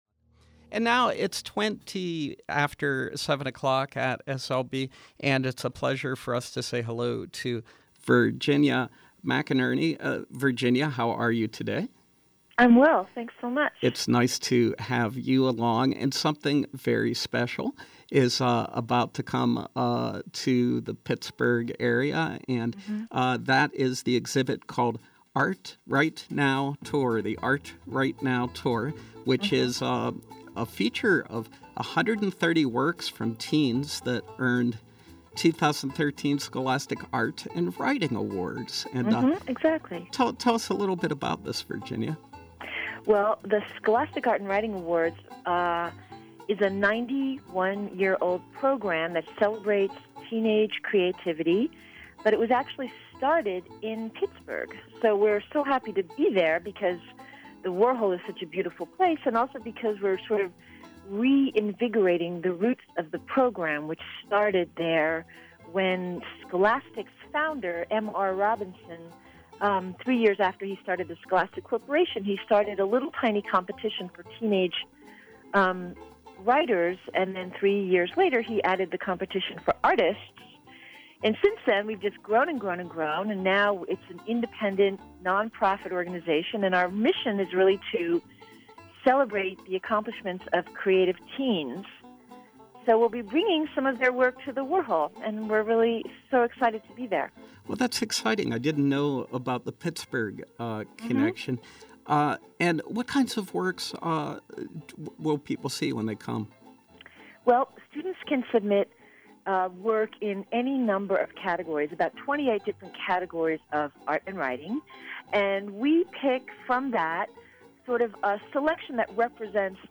Home » Interviews